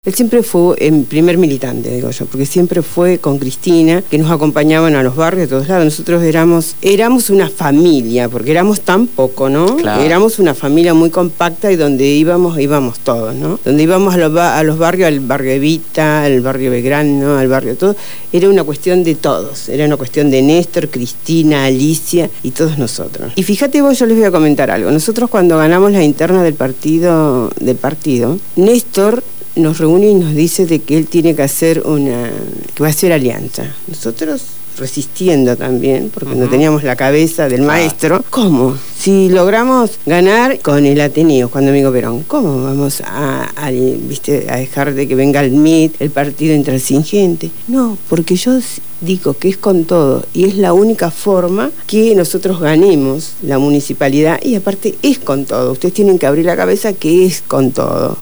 Estuvieron en los estudios de Radio Gráfica FM 89.3 durante el programa «Punto de partida» y nos conducieron por un relato desde las primeras internas que culminan llevándolo a la Intendencia de Río Gallegos, las anécdotas, las dificultades en la función de gobierno, sus sueños y las horas interminables de trabajo desde la visión de dos militantes de la primera hora.